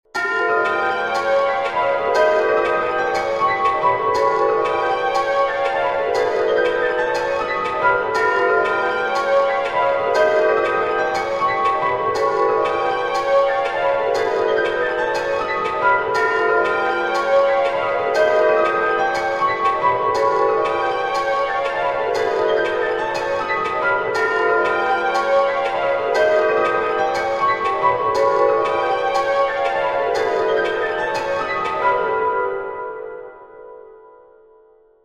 Groove in 6edo (again)
6edo_groove_2.mp3